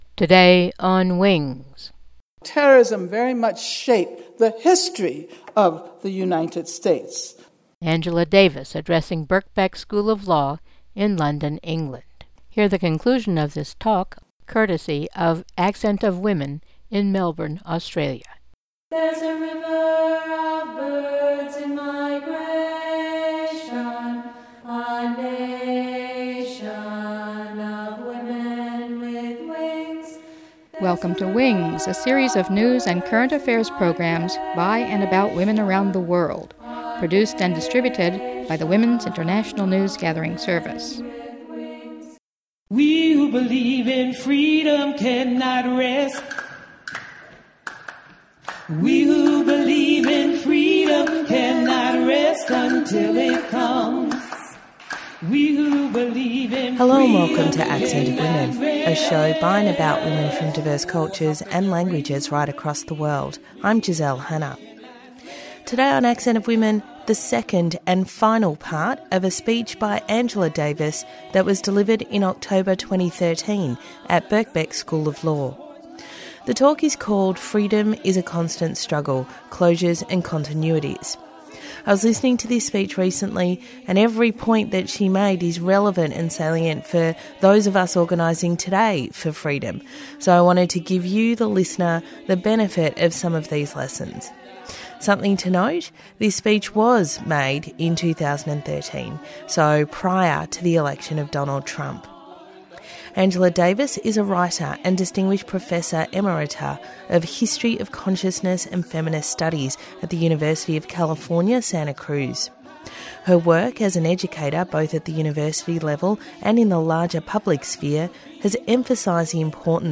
Her talk Freedom Is a Constant Struggle, Part 2